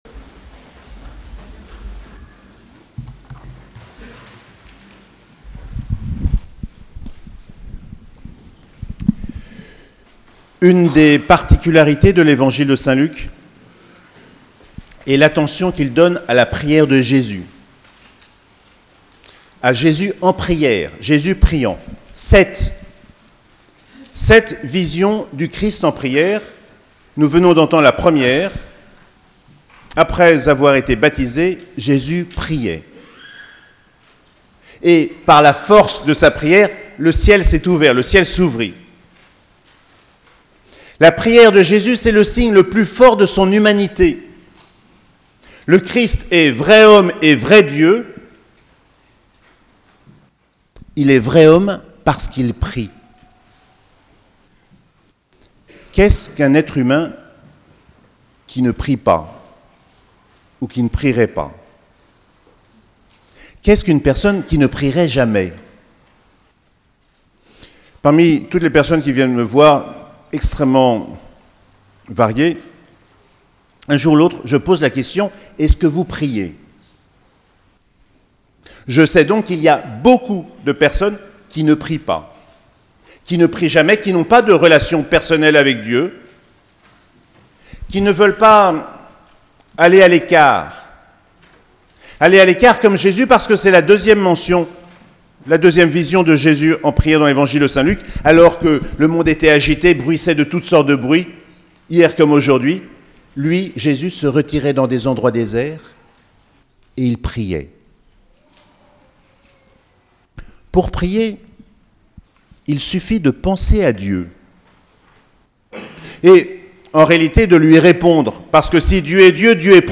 Baptême du Seigneur - 13 janvier 2019